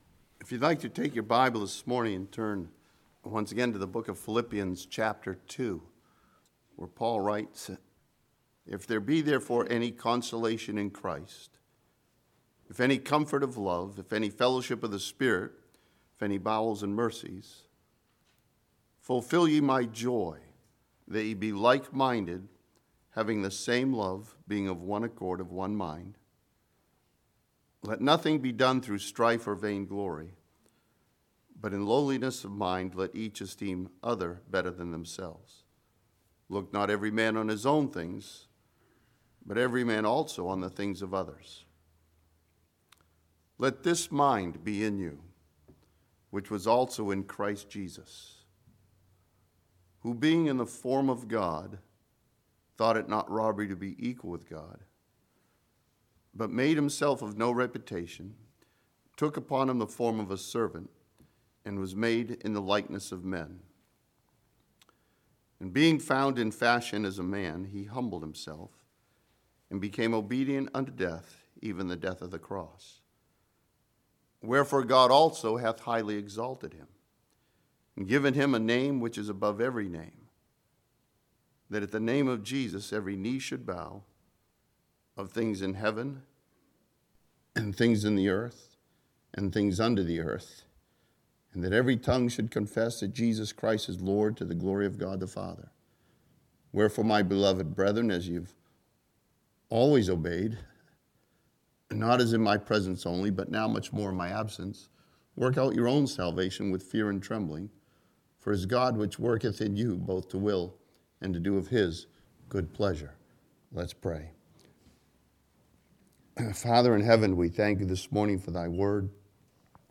This sermon from Philippians chapter 2 studies the death of the cross and what that means for the believer.